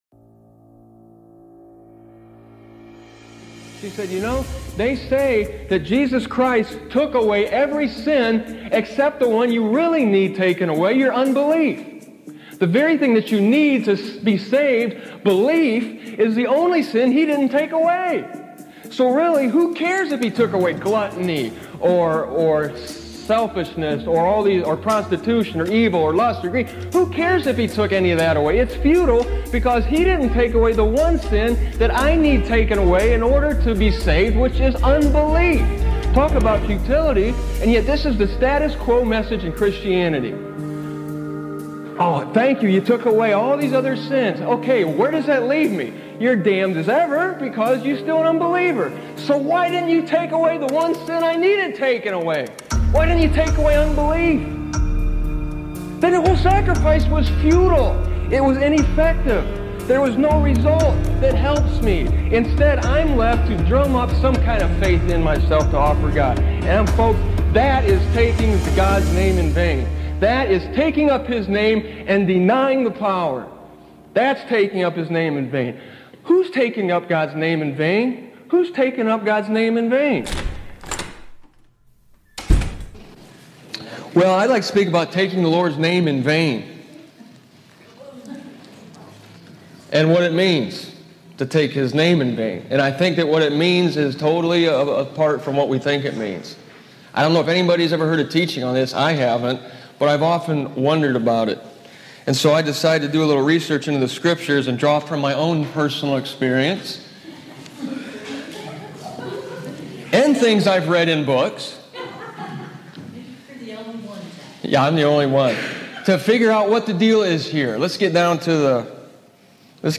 MZ/IB Archive - Truth Series Taking God's Name in Vain Dear Fellow Believers, From Willard, OH, in 1997, I delivered this teaching on the commandment (number three) warning Israel not to take God's name in vain.